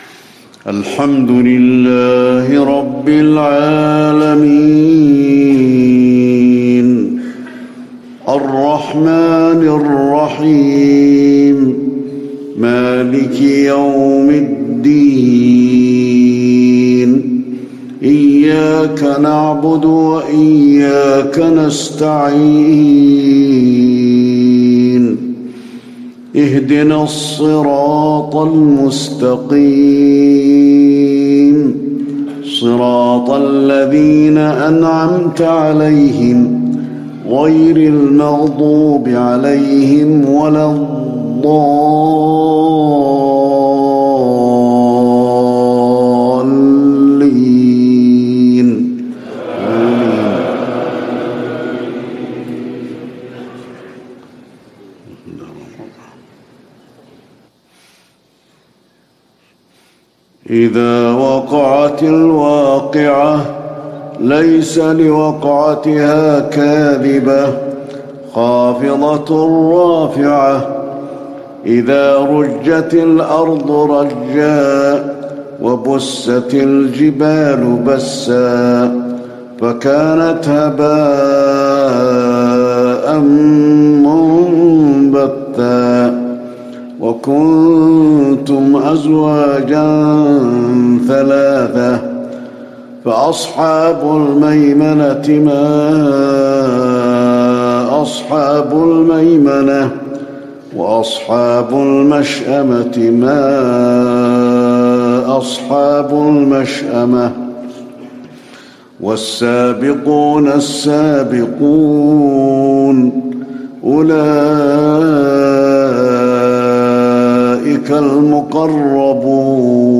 فجر 1 شعبان 1440 هـ من سورة الواقعة | Fajr prayer from Surat Al-Waqia 6- 4- 2019 > 1440 🕌 > الفروض - تلاوات الحرمين